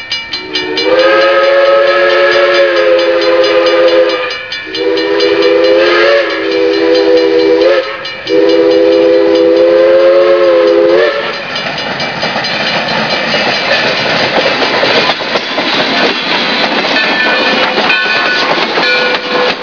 whistling for Toad Lane grade crossing while running southbound to Ringoes during the 1999 season